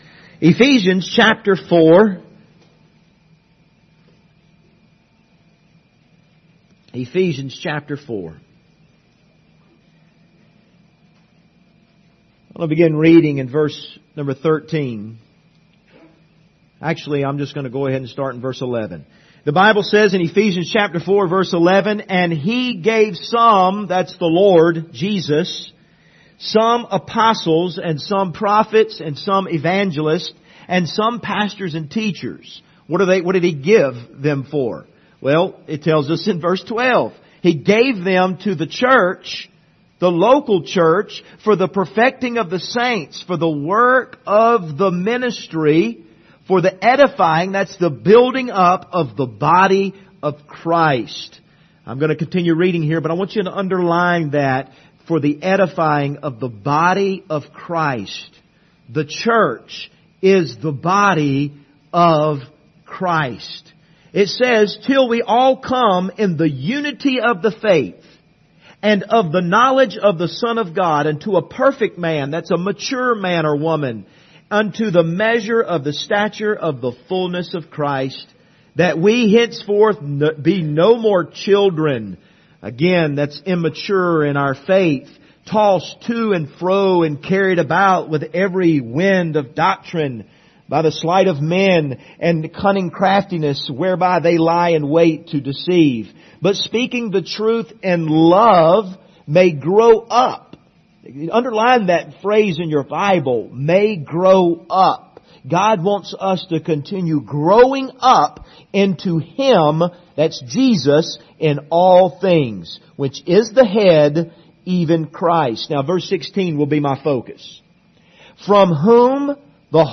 Service Type: Sunday Morning View the video on Facebook Topics: service , the body of Christ « Brokenness Brings Blessings Judge Not